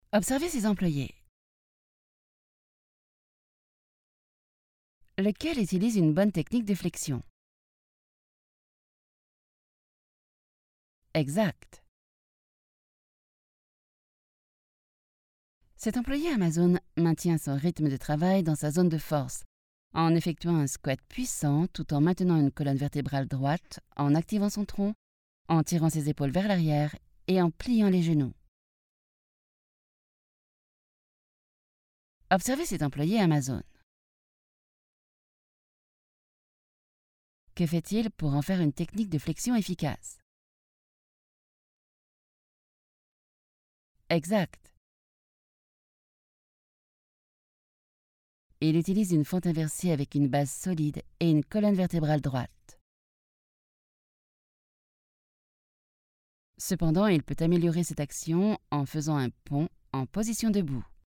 Vídeos corporativos
Mi voz es cálida, femenina y es ideal para narraciones, aprendizaje electrónico, audiolibros, audioguías, pero también para algunos anuncios y juegos.
Micro Neumann 103
Una cabina Isovox en una cabina insonorizada